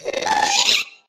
Sound / Minecraft / mob / ghast / charge / death.ogg
death.ogg